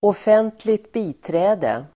Uttal: [åf'en:tli(k)t ²b'i:trä:de]